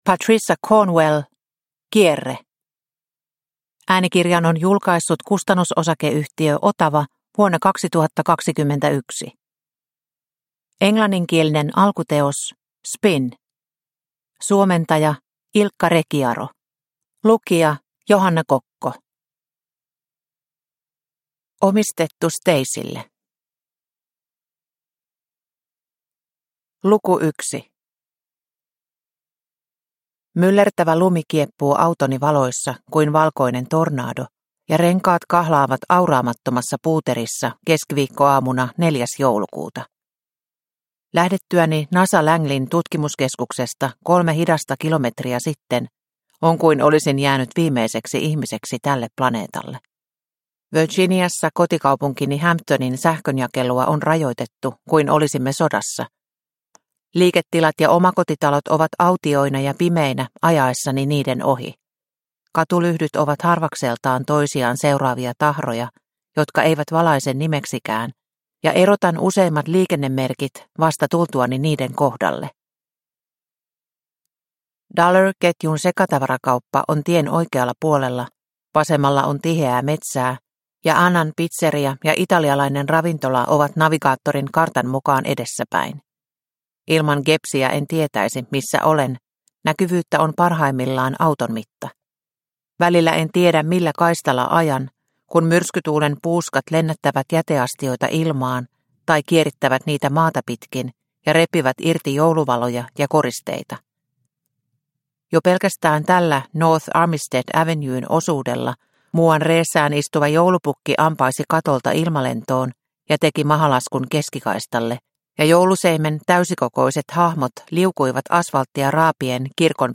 Kierre – Ljudbok – Laddas ner